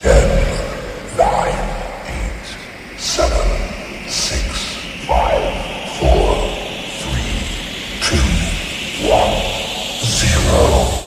Timer.wav